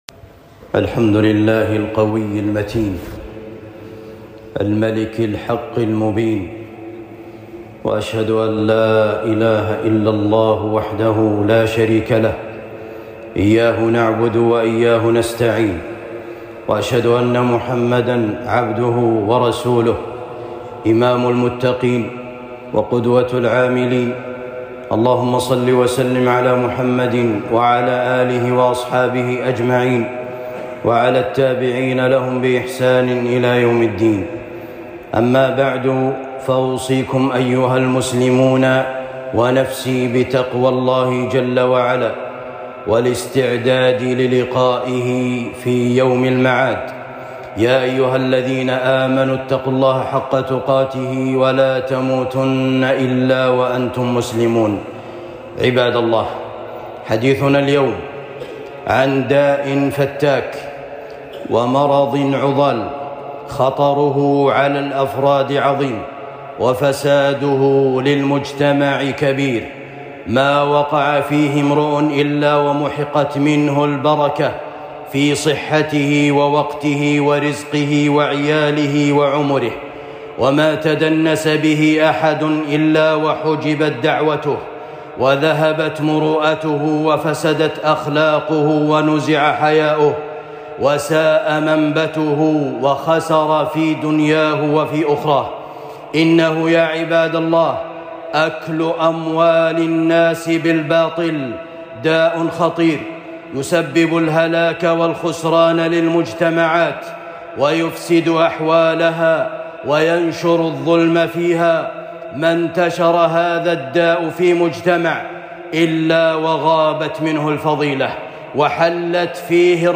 صوره ووسائل الوقاية منه الصوتيات الخطب الصوت أكل أموال الناس بالباطل